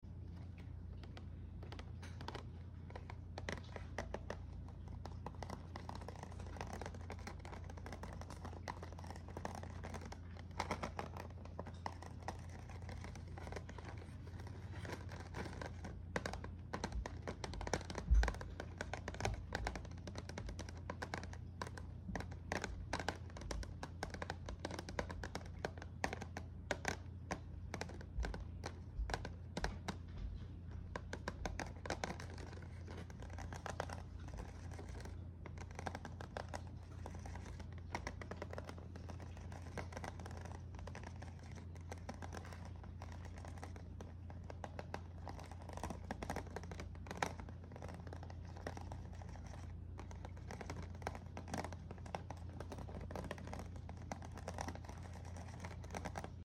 +eating sounds from my cats sound effects free download